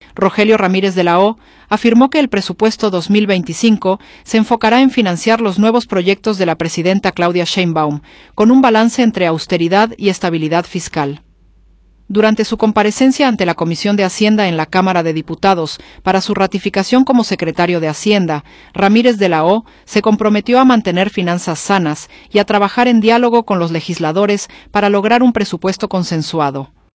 Rogelio Ramírez de la O afirmó que el Presupuesto 2025 se enfocará en financiar los nuevos proyectos de la presidenta, Claudia Sheinbaum, con un balance entre austeridad y estabilidad fiscal. Durante su comparecencia ante la Comisión de Hacienda en la Cámara de Diputados para su ratificación como Secretario de Hacienda, Ramírez de la O se comprometió a mantener finanzas sanas y a trabajar en diálogo con los legisladores para lograr un presupuesto consensuado.